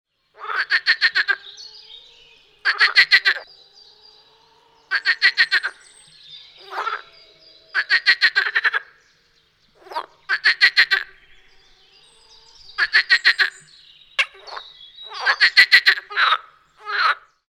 Audiodateien, nicht aus dem Schutzgebiet
Seefrosch UB
2025_Seefrosch_Einzelrufe_short.mp3